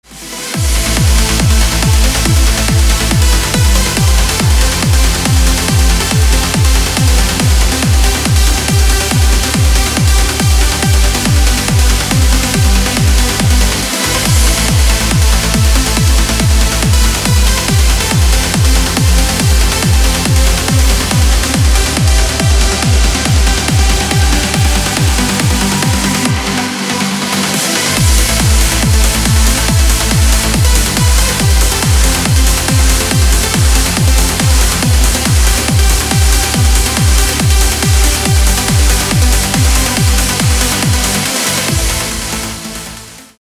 громкие
dance
Electronic
электронная музыка
без слов
club
Trance
Uplifting trance